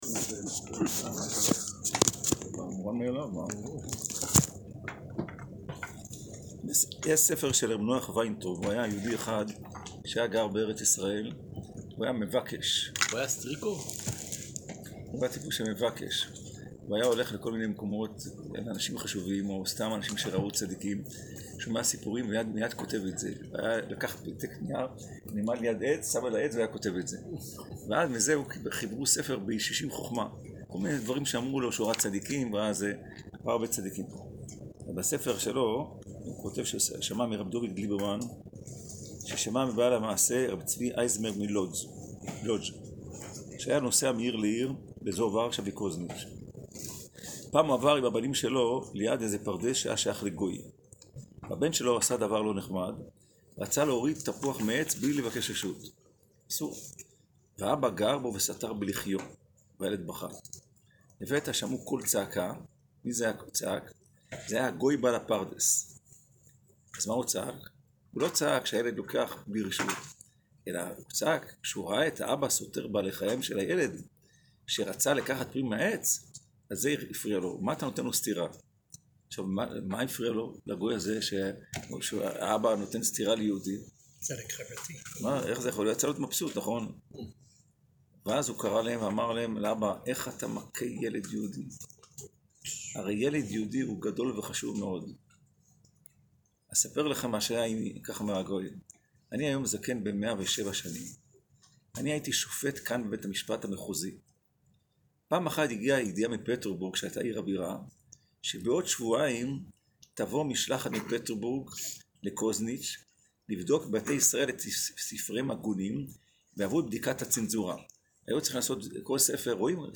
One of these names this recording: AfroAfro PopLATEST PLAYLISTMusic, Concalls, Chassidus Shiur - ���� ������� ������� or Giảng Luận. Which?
Chassidus Shiur - ���� ������� �������